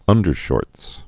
(ŭndər-shôrts)